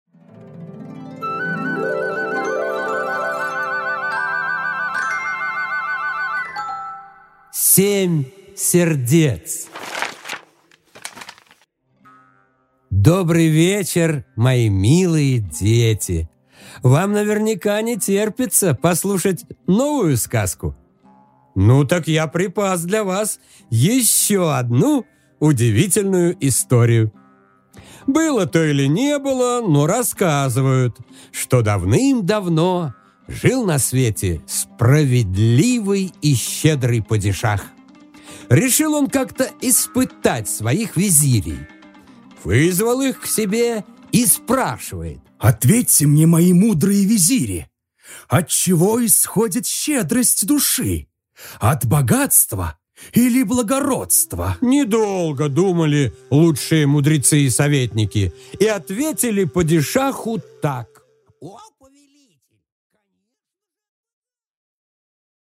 Аудиокнига Семь сердец